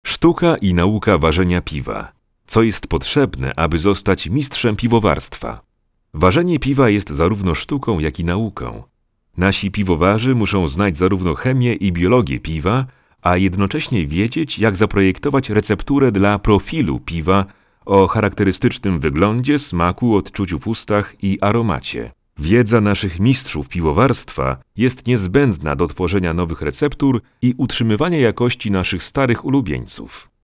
Diep, Toegankelijk, Veelzijdig, Vertrouwd, Commercieel
Audiogids